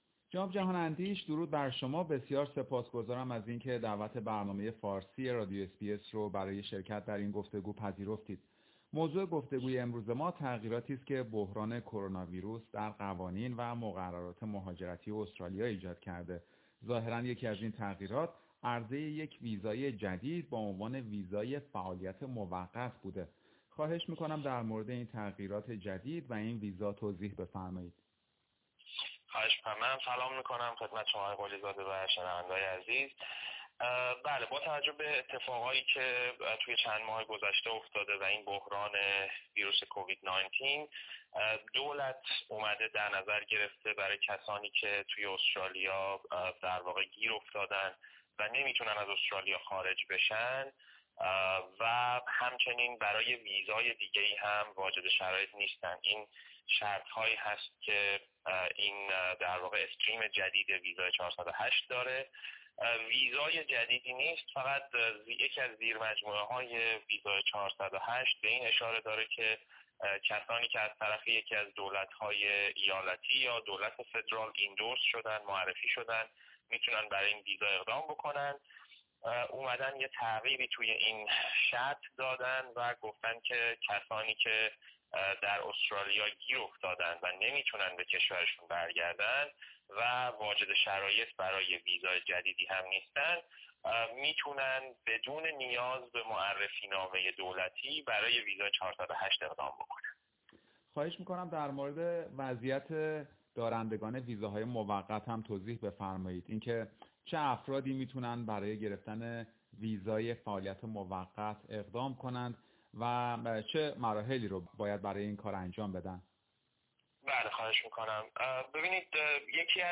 برنامه فارسی رادیو اس بی اس گفتگویی داشته